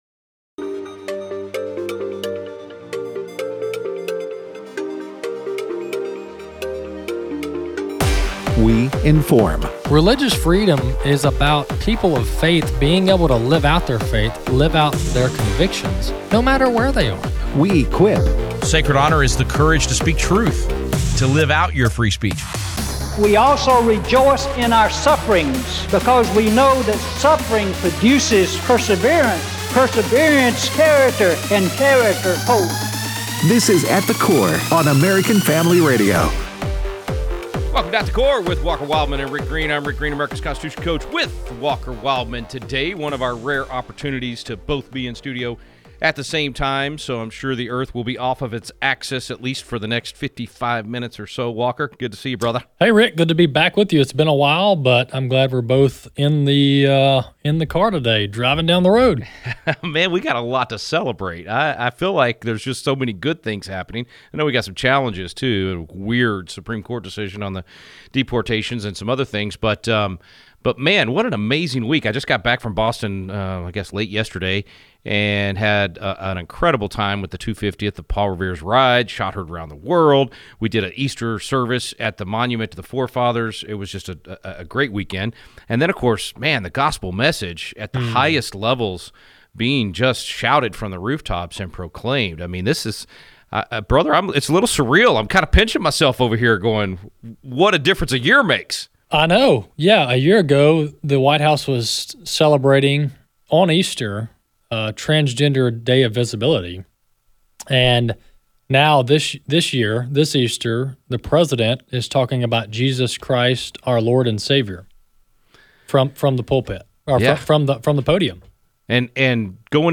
Callers weigh in